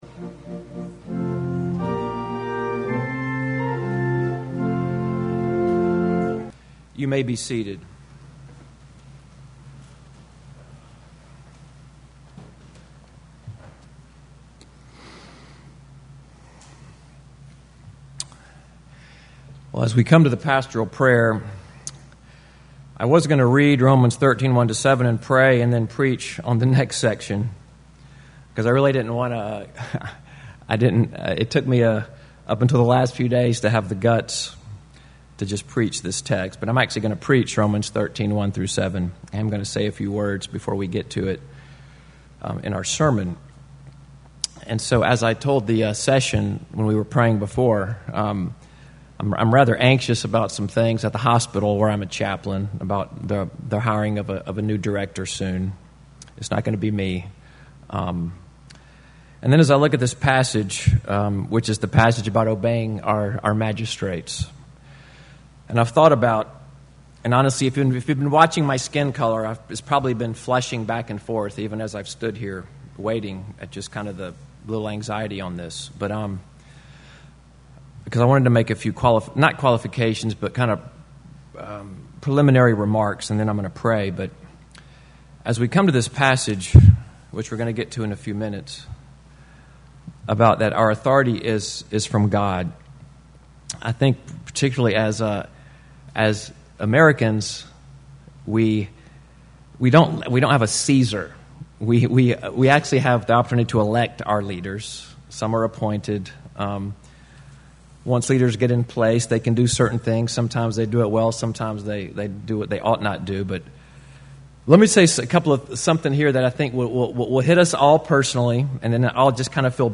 Pastoral Prayer